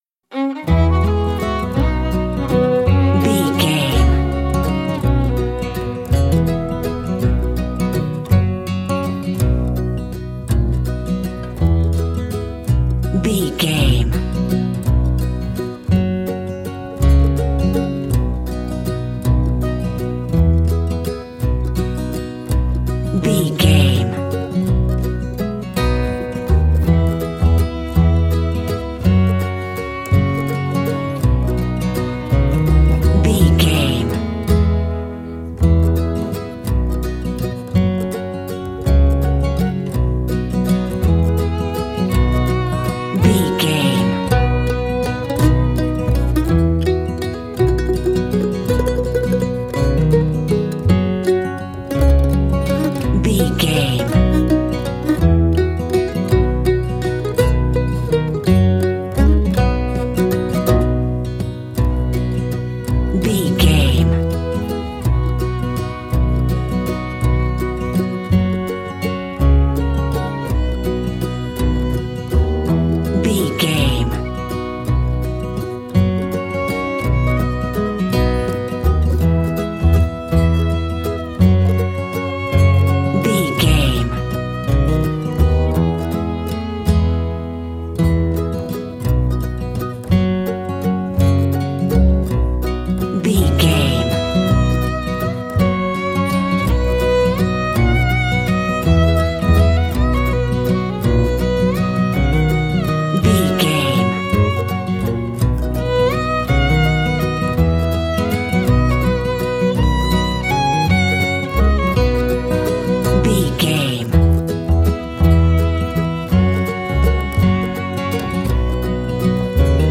Ionian/Major
acoustic guitar
bass guitar
violin
bluegrass